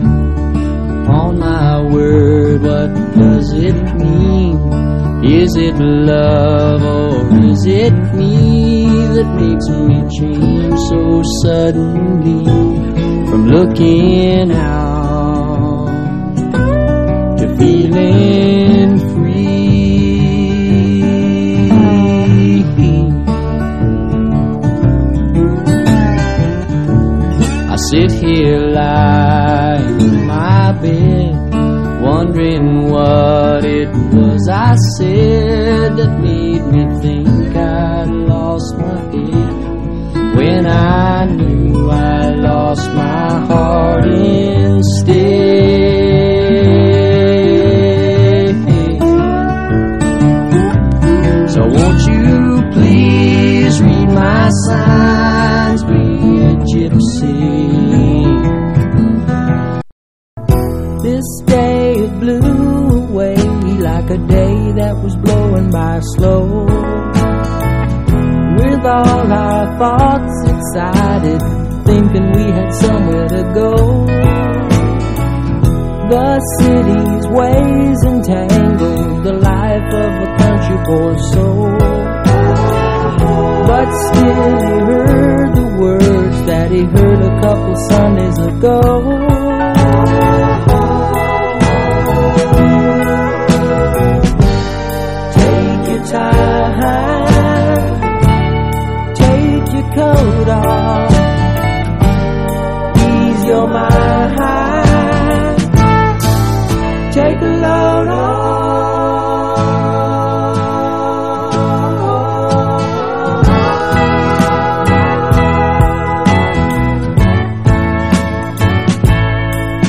ROCK / 70'S / SWAMP ROCK / PSYCHEDELIC ROCK / COUNTRY ROCK
71年サイケデリック風味ハード・カントリー・ロック唯一作！